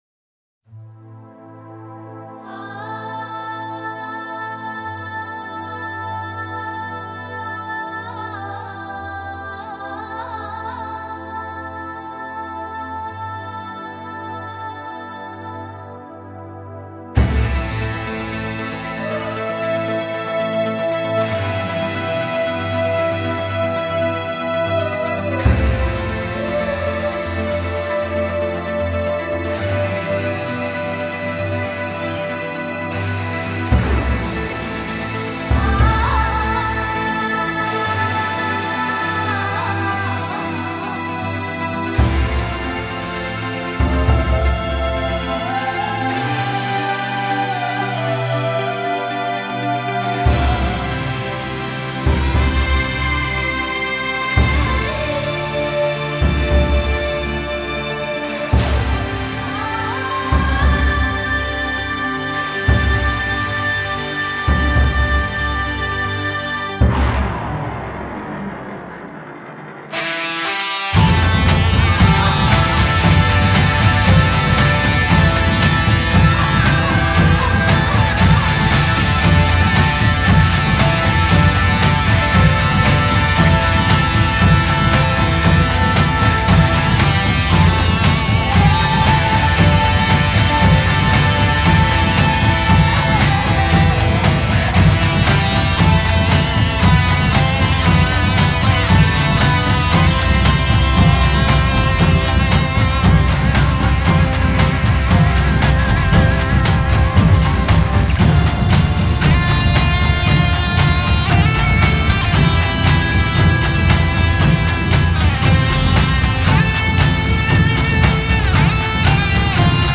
唱片類型：New Age
感受一下太鼓的震撼 ,心室随着鼓声擂动~